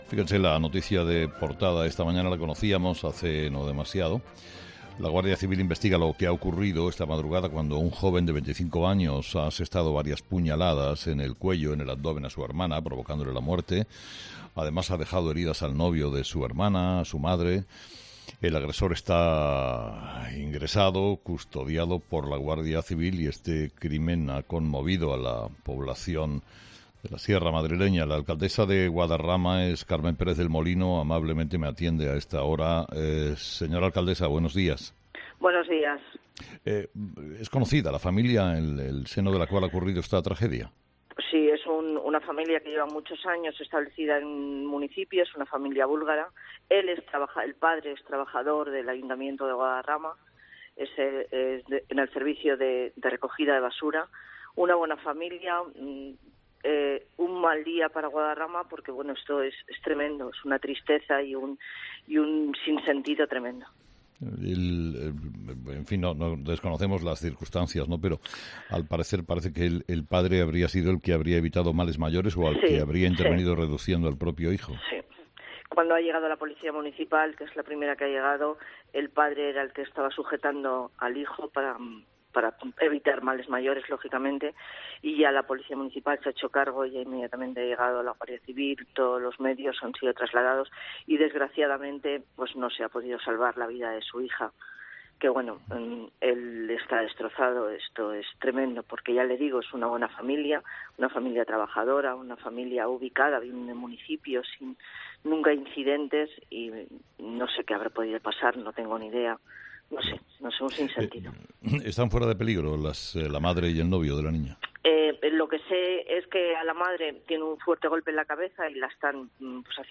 La alcaldesa de Guadarrama, Mari Carmen Pérez del Molino, en 'Herrera en COPE'